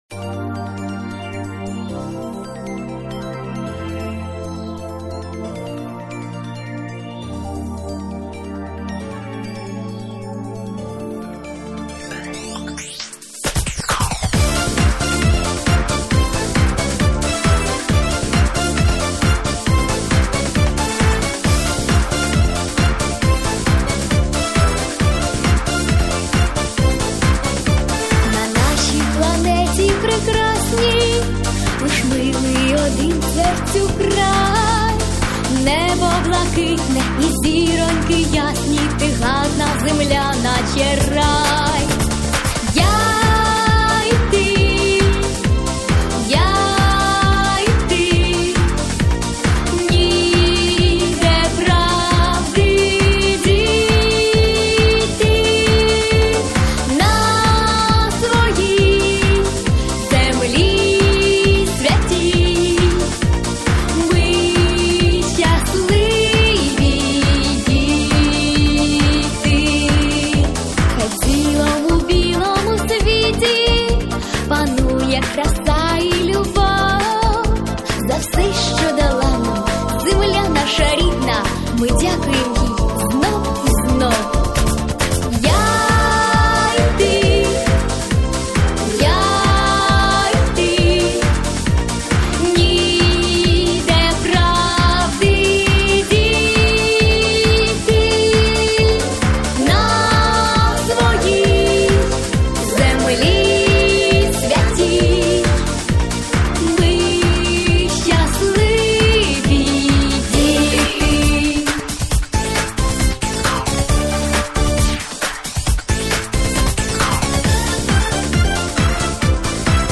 Плюсовий запис